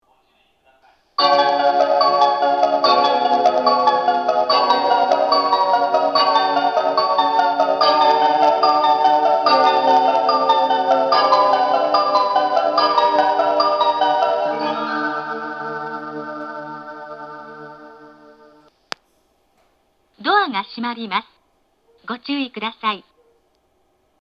当駅は放送装置の調子が悪いのか、接近チャイムや発車メロディーを切った時ににノイズが入ります。
放送更新前の音声
発車メロディー
フルコーラスです。